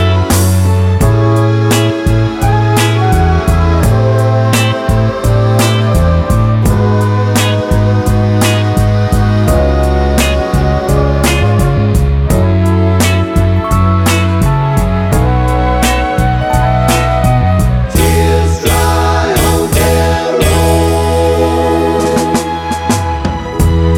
original version Pop (2000s) 4:09 Buy £1.50